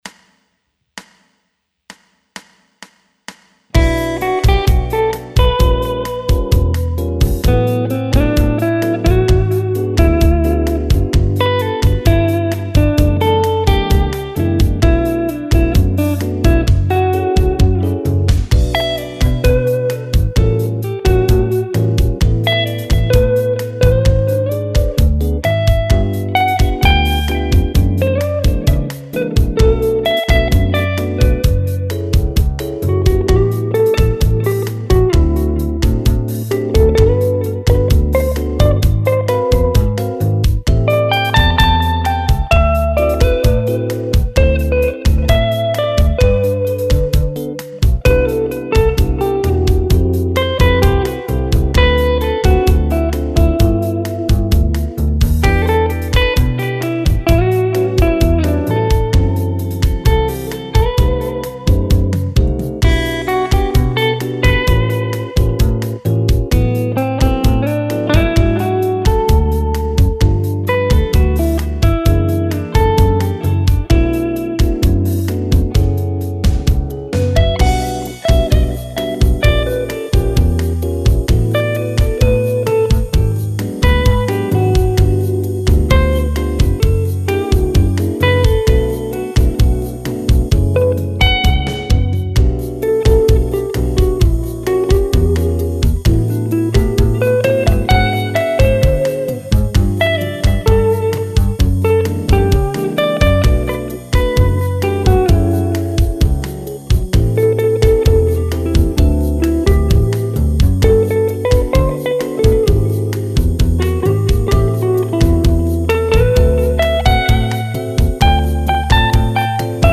relaxte Backing
Das ist ja mehr aus der Jazzecke und deshalb hier nun: